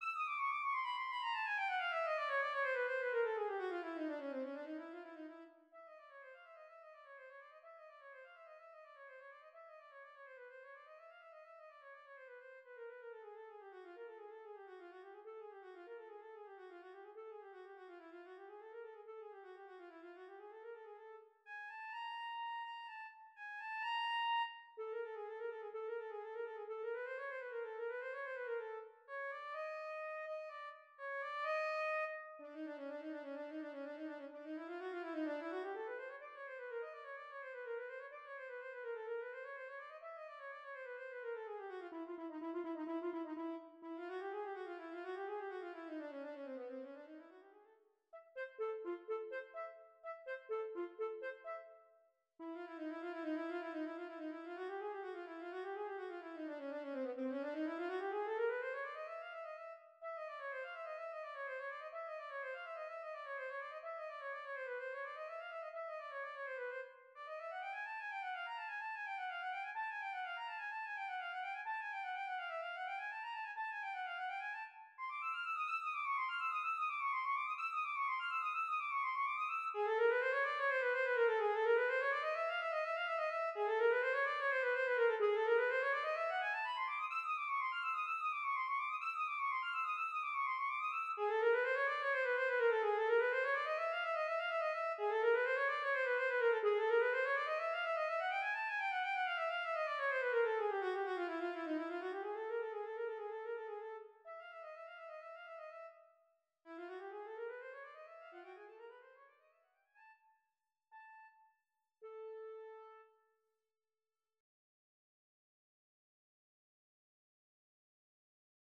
Voicing: Alto Saxophone